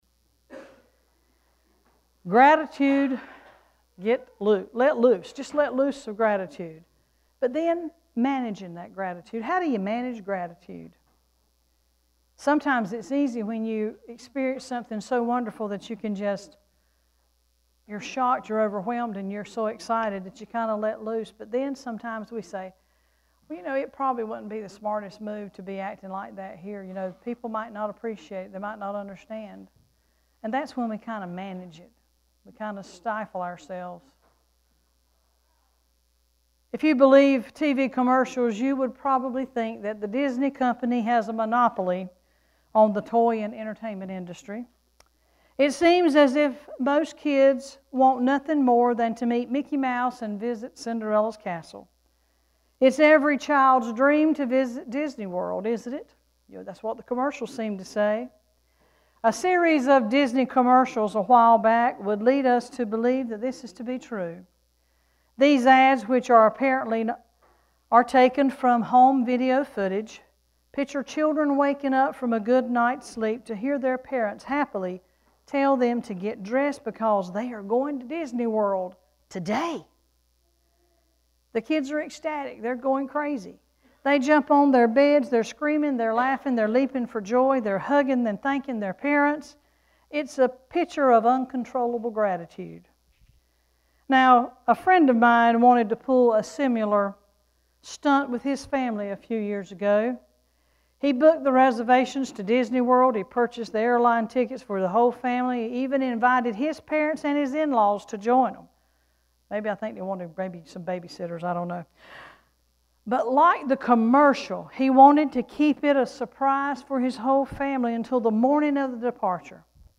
11-13-sermon.mp3